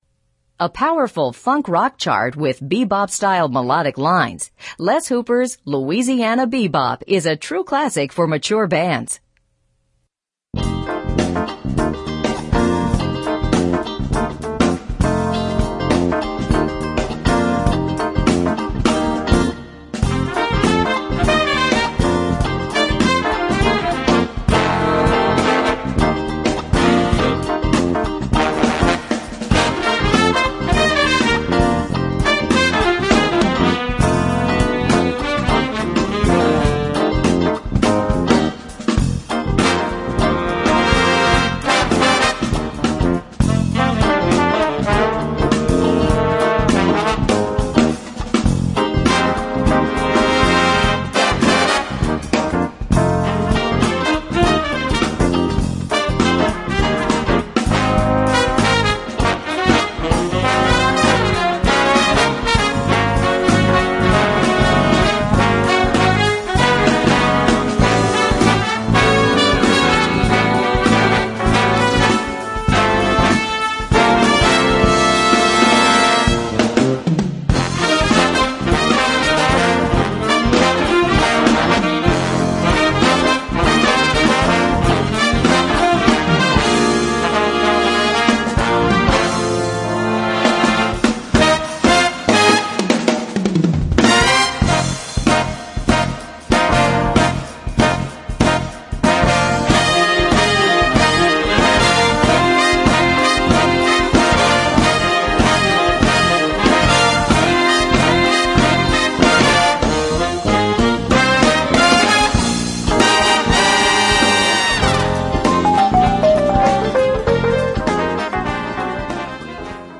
Voicing: Jazz Ensemble Level